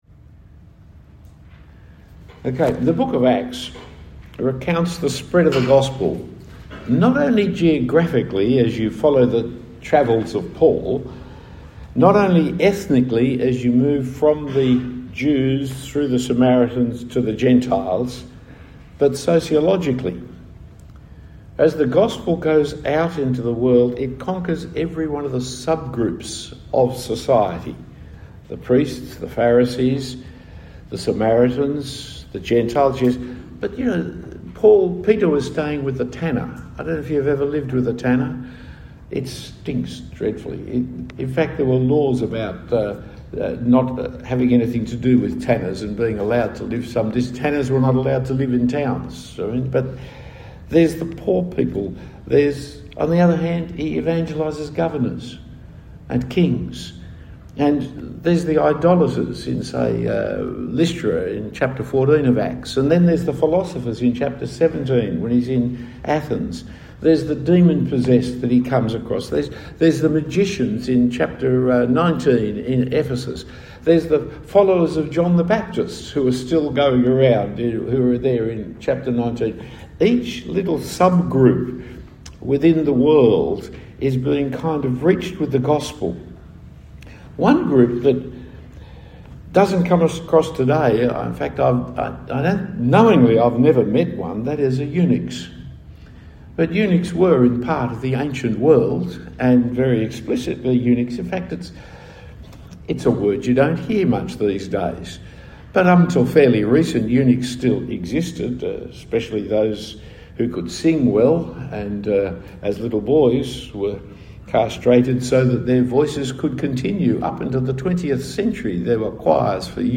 Talk 1 of 2 given at the FIEC Pastors’ Conference.